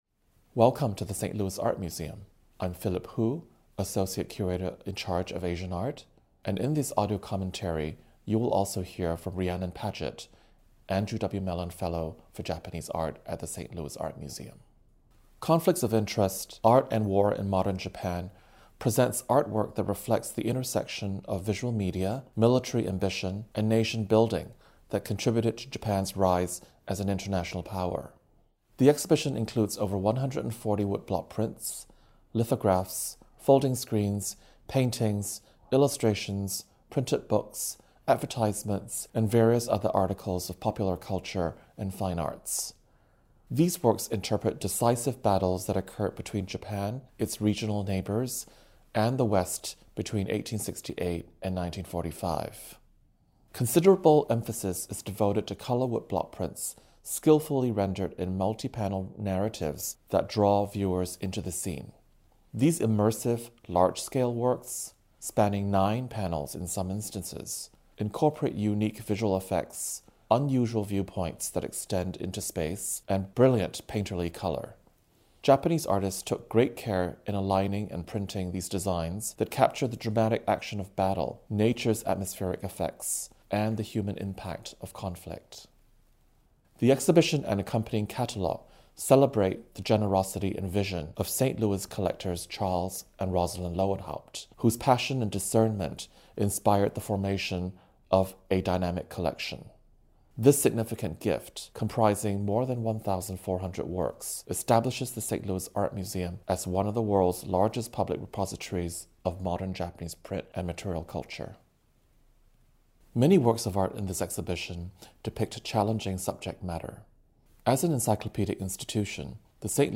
This audio guide features an introduction in both English and Chinese, and expert commentary on 8 works of art from the exhibition.